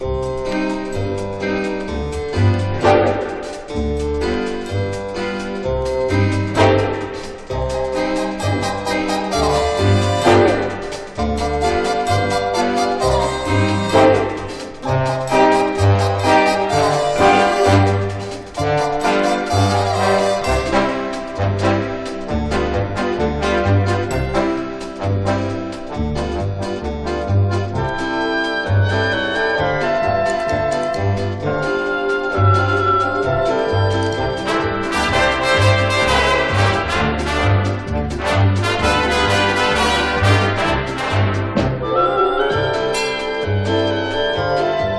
Jazz, Swing, Easy Listening　USA　12inchレコード　33rpm　Mono